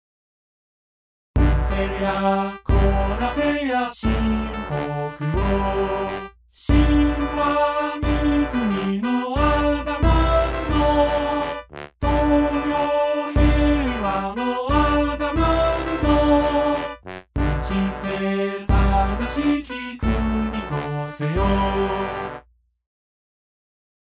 ところで上の「討てや懲らせや」の演奏は、7月26日の夏季セミナーのために、今回とりあえず VOCALOID で作ってみたものです。